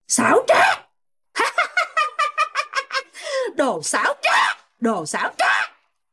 Thể loại: Câu nói Viral Việt Nam
Đây là một meme sound effect đang viral rất mạnh trên TikTok.